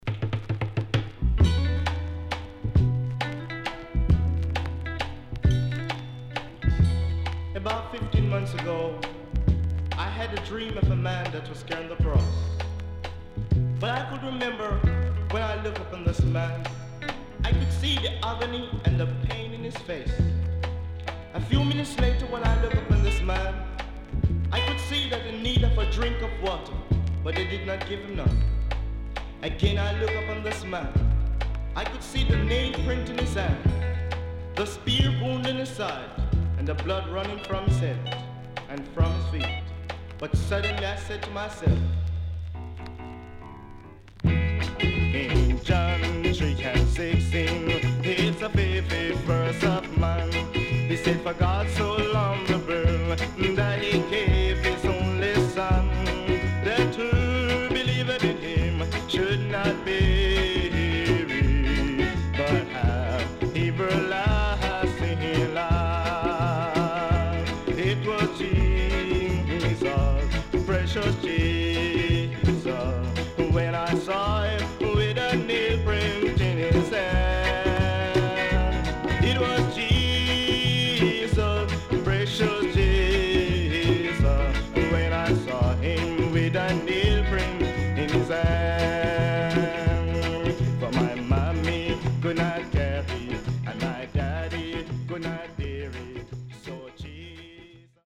Gospel.Nice Vocal
SIDE A:所々チリノイズがあり、少しプチノイズ入ります。